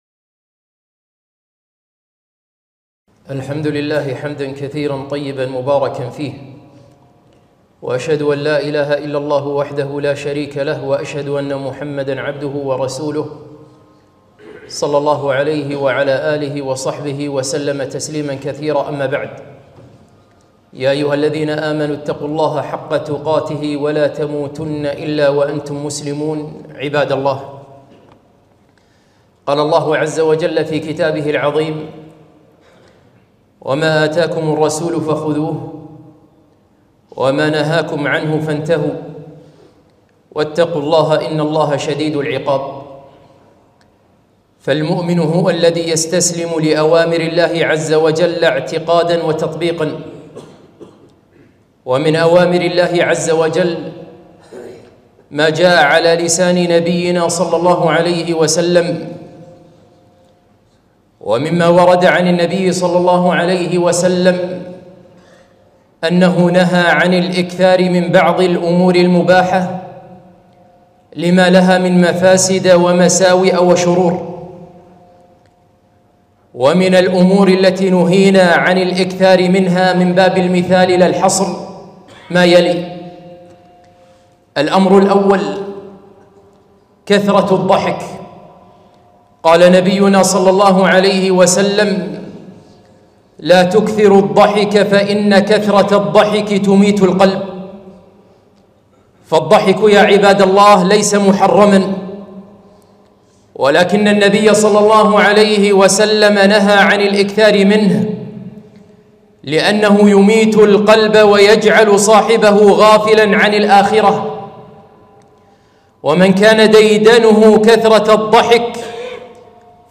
خطبة - ثمانية أشياء لا تكثر منها